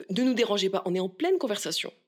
VO_ALL_EVENT_Trop proche de la cible_01.ogg